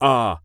Male_Grunt_Hit_Neutral_01.wav